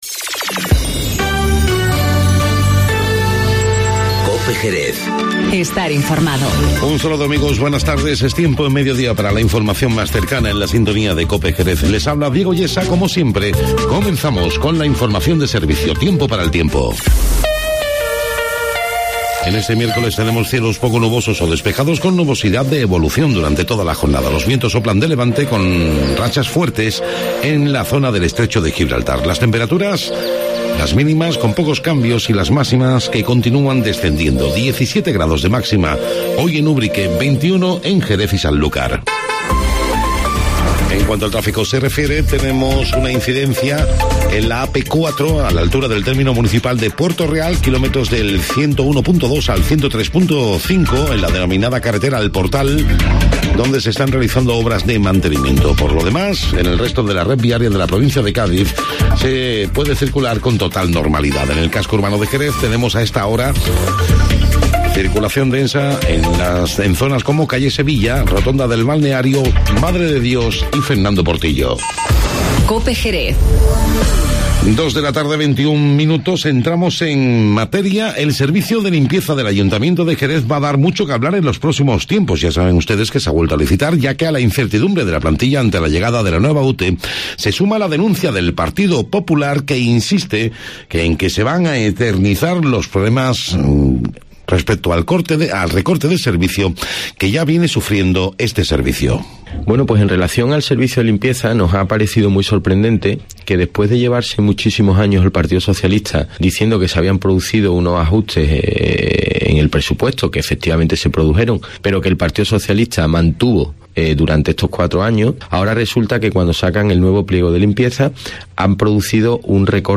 Informativo Mediodía COPE Jerez